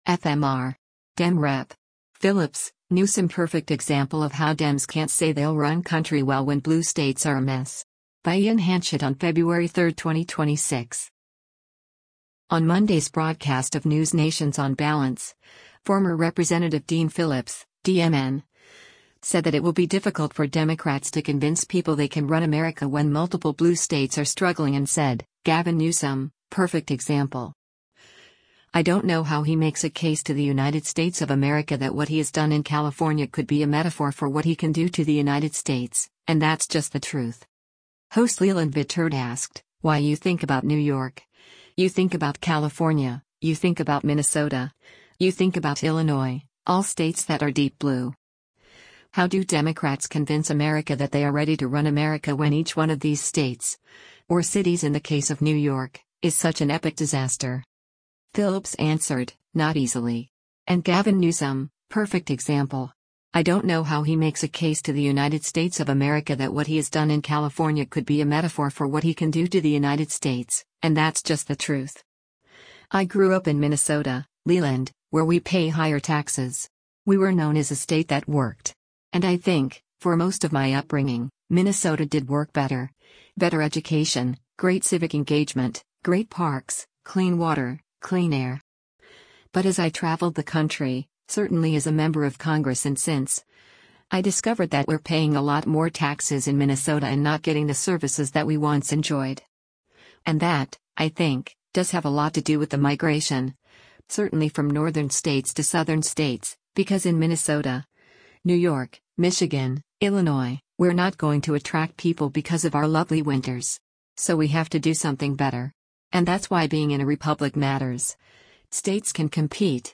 On Monday’s broadcast of NewsNation’s “On Balance,” former Rep. Dean Phillips (D-MN) said that it will be difficult for Democrats to convince people they can run America when multiple blue states are struggling and said, “Gavin Newsom, perfect example. I don’t know how he makes a case to the United States of America that what he’s done in California could be a metaphor for what he can do to the United States, and that’s just the truth.”